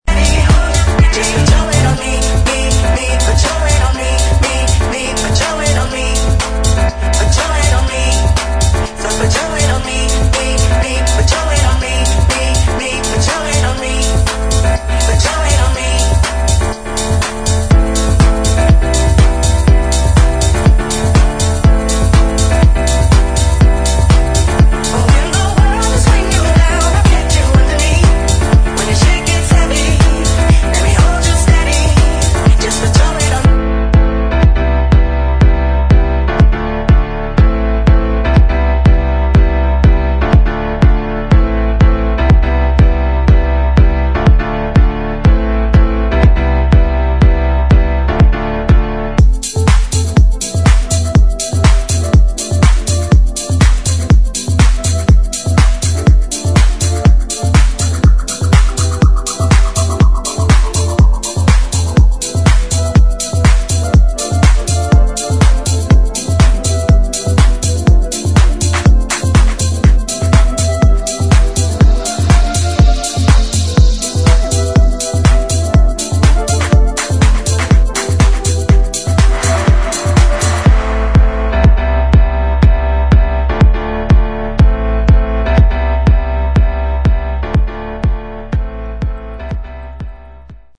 [ HOUSE | TECHNO ]